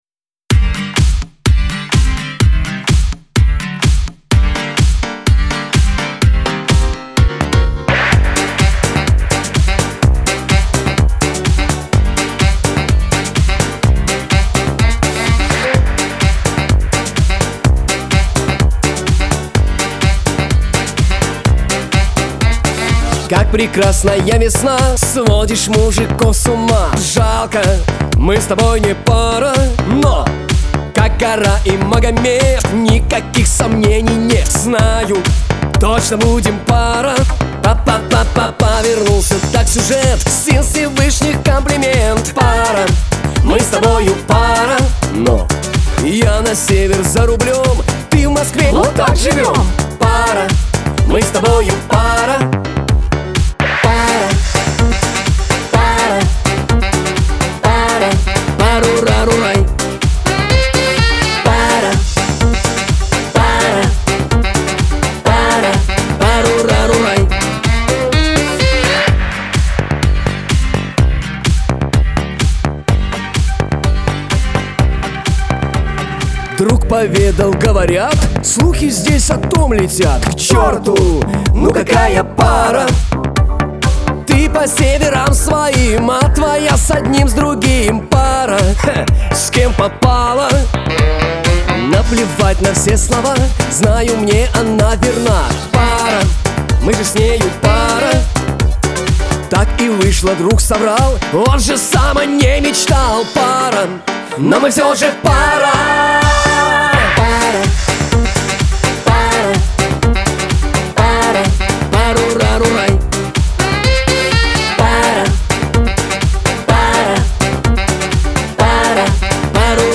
Категория: Шансон 2016